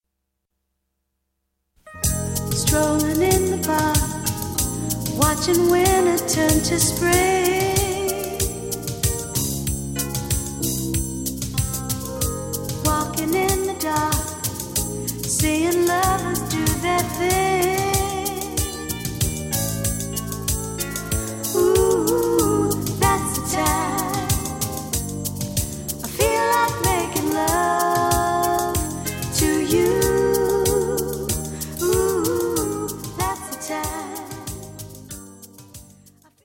Recueil pour Chant/vocal/choeur